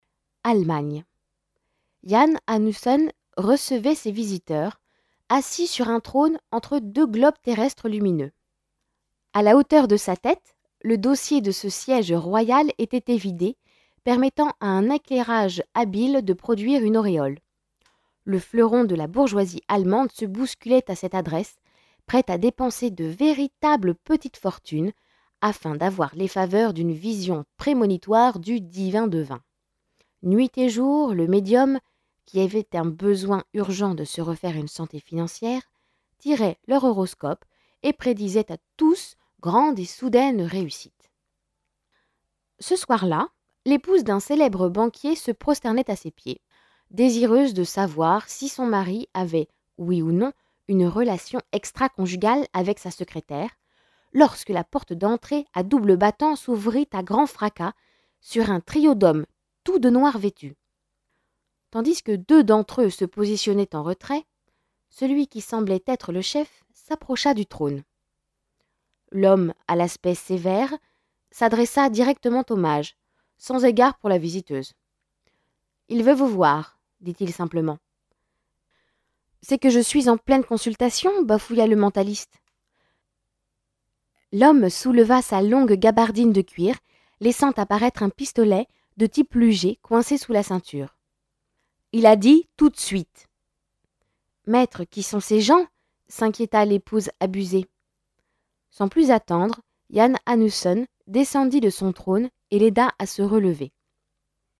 rencontre à la bibliotheque du touvet sur radio gresivaudan